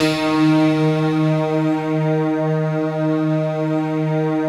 SI1 PLUCK04L.wav